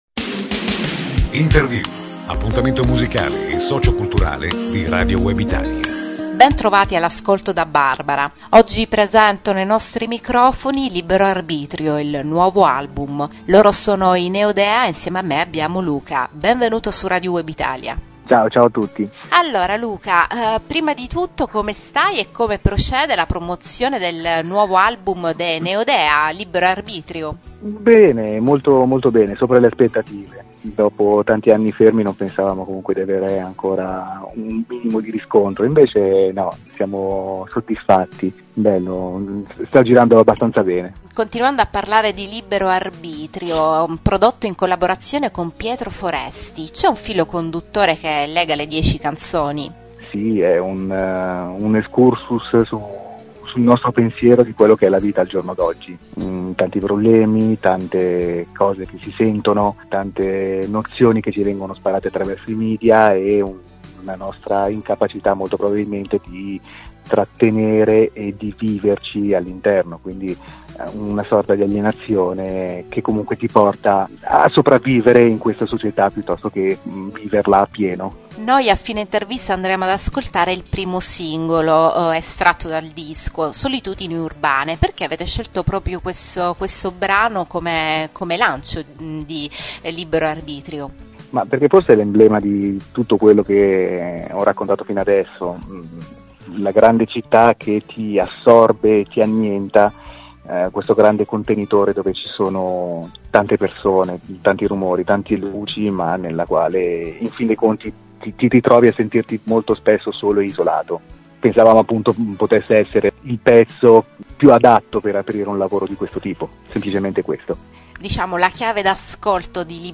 “Libero Arbitrio” il nuovo album dei Neodea. Intervista - Radio Web Italia
neodea-intervista.mp3